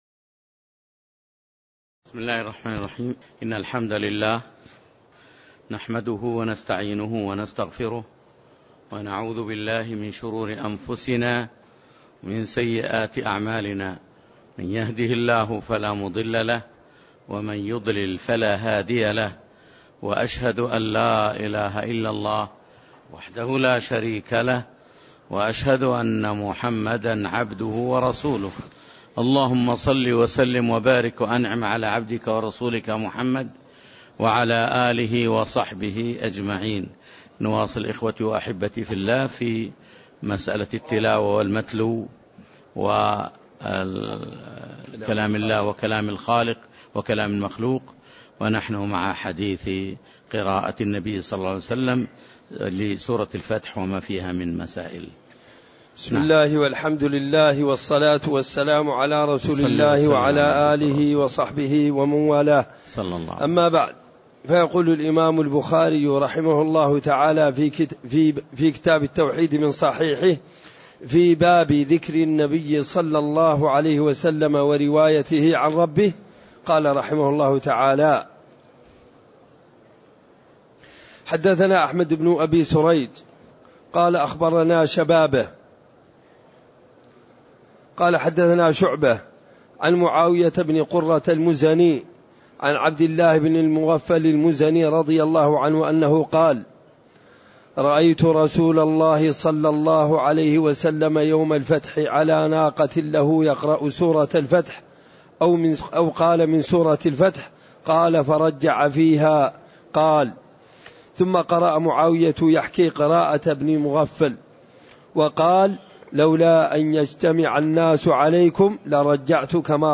تاريخ النشر ٣ جمادى الأولى ١٤٣٦ هـ المكان: المسجد النبوي الشيخ